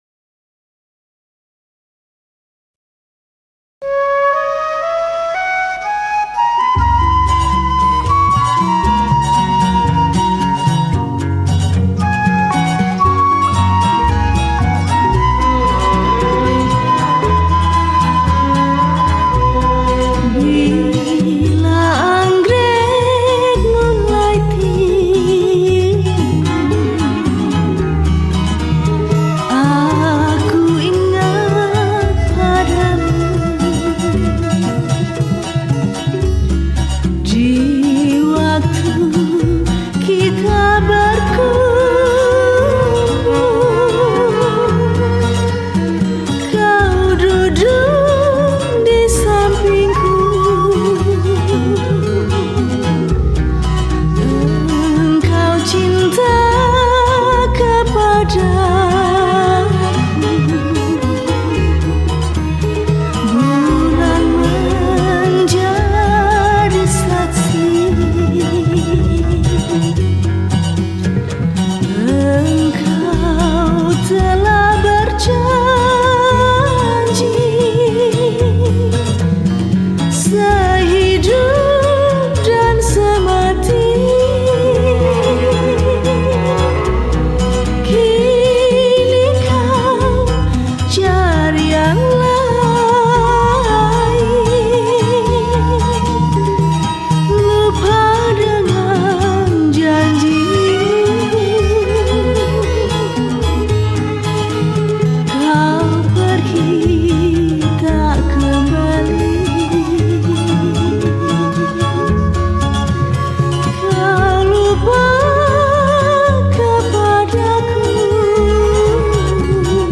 Keroncong Asli